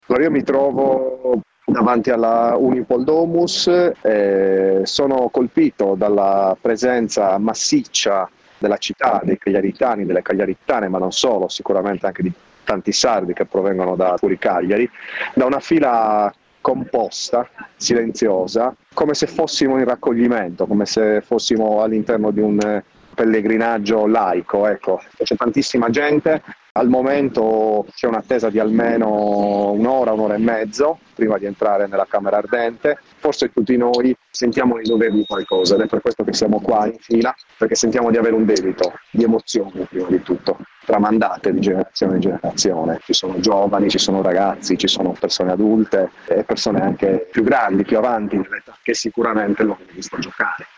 Il racconto della giornata di martedì 23 gennaio 2024 con le notizie principali del giornale radio delle 19.30. I bombardamenti nel sud della striscia di Gaza in queste ore si sono intensificati e l’esercito israeliano ha completamente accerchiato la città di Khan Younis.